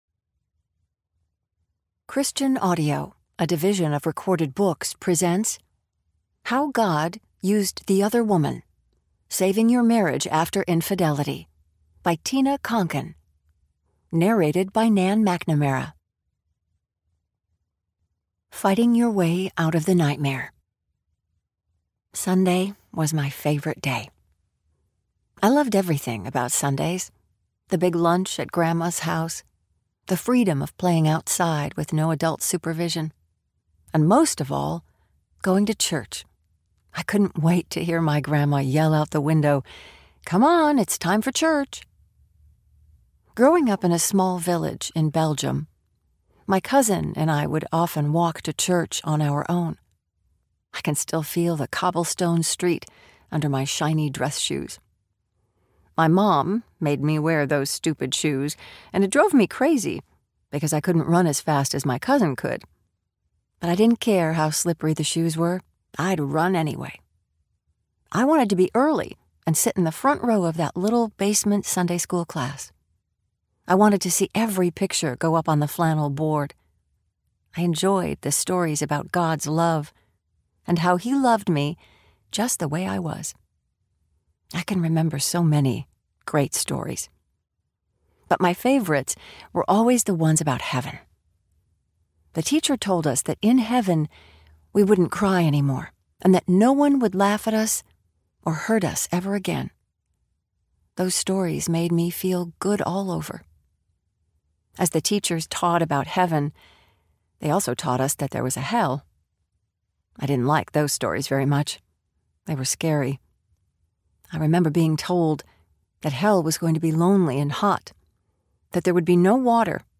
How God Used ‘the Other Woman’ Audiobook
Narrator
6.76 Hrs. – Unabridged